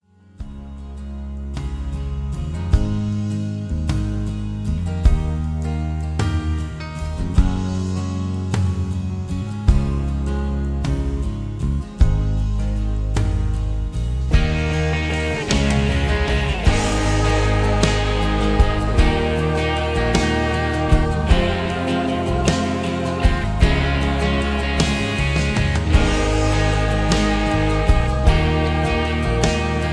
Key-Ab
mp3 backing tracks